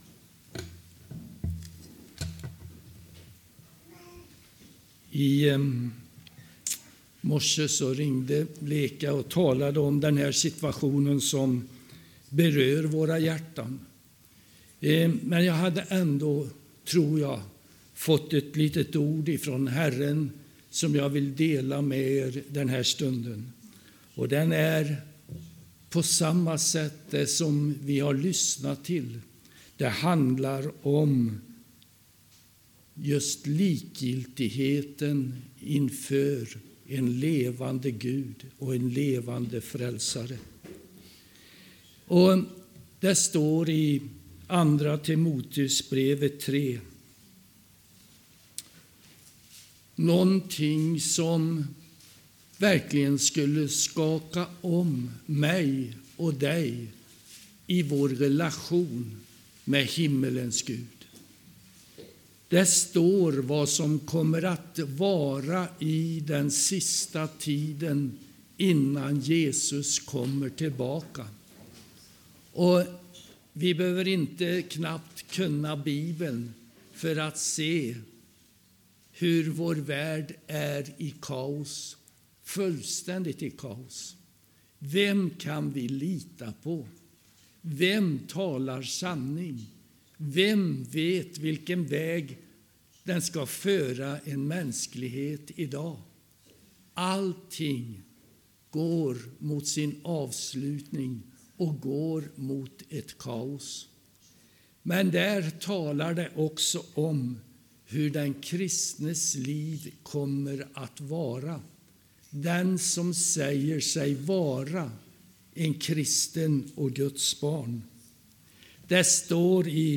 Här kan ni lyssna på predikningar från bl.a. Ardala och Betania.